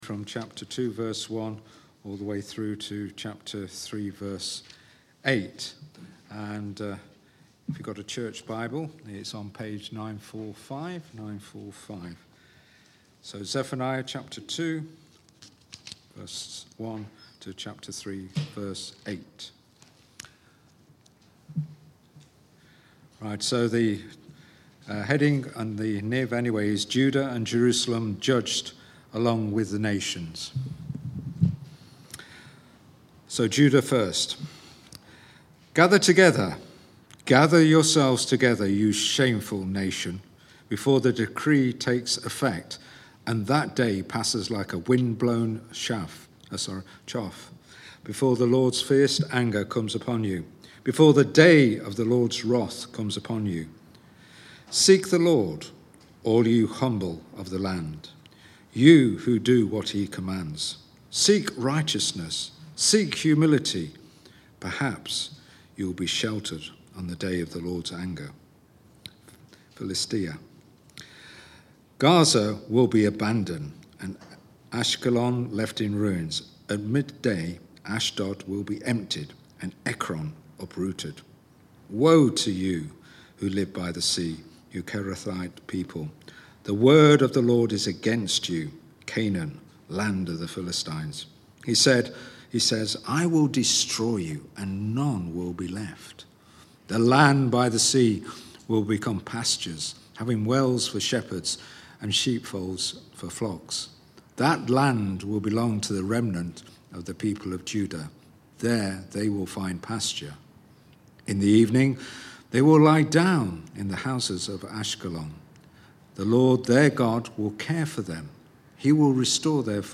Destruction Is Coming (Zephaniah 2:1-3:8) from the series Seek the LORD. Recorded at Woodstock Road Baptist Church on 07 May 2023.